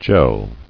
[gel]